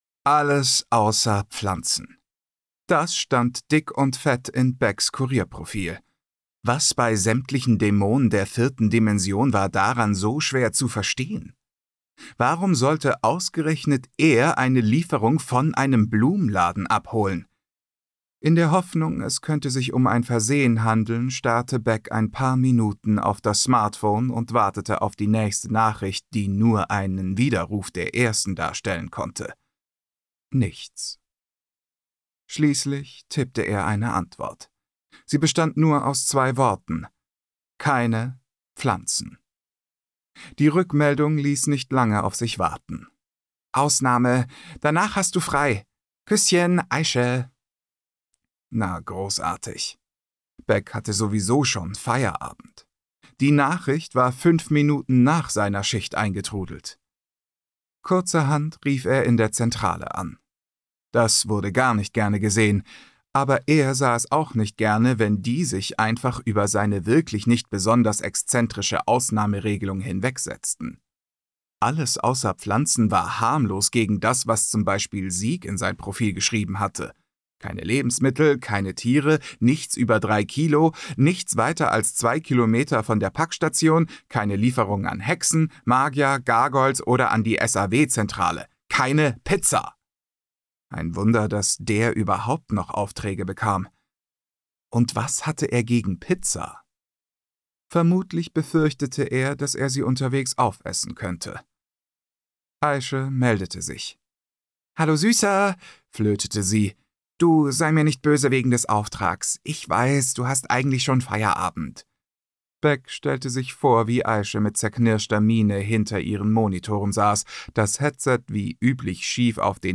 Wünschelbräu Premium Hörbuch
Wuenschelbraeu-Hoerprobe.mp3